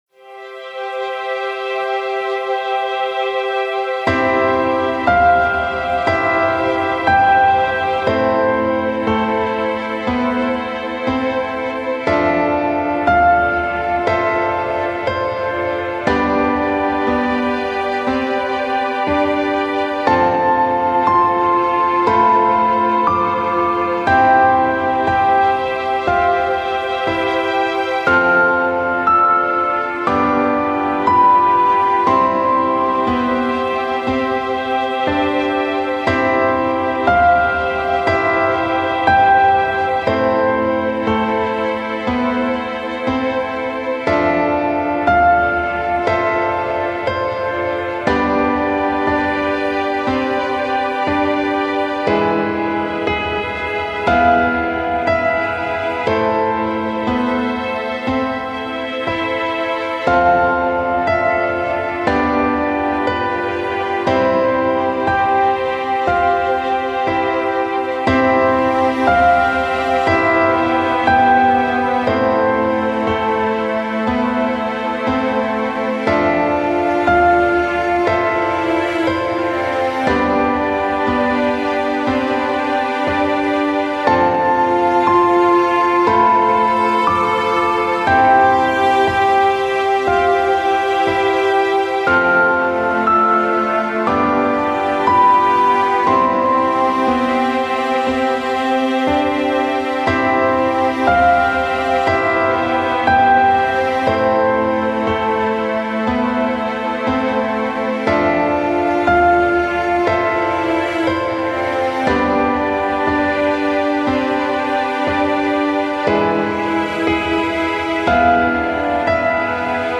【用途/イメージ】 尊き愛 天国 美しさ 出会い 別れ 涙 メモリアル